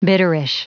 Prononciation du mot bitterish en anglais (fichier audio)
Prononciation du mot : bitterish